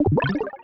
Magic5.wav